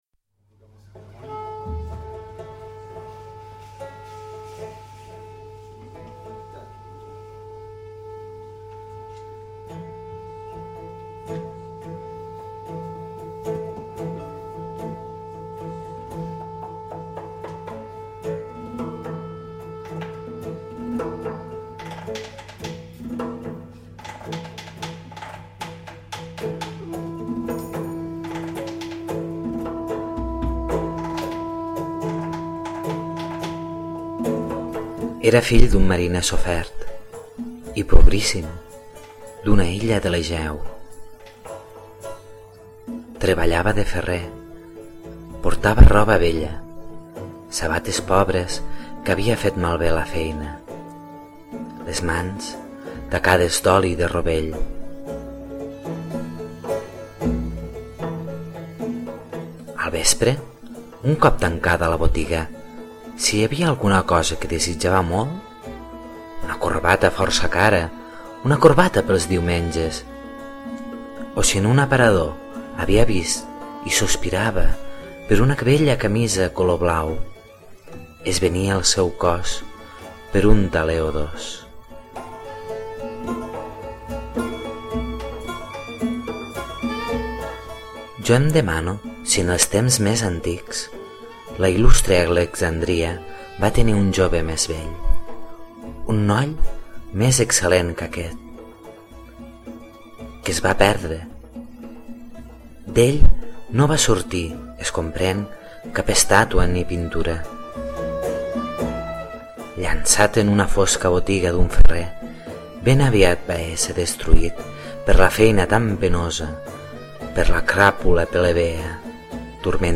Per fer una cosa diferent he optat per este magnífic poema de Kavafis, amb música de Faren Khan (la cançó és la titulada Danse grecque) .